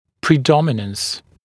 [prɪ’dɔmɪnəns][при’доминэнс]преобладание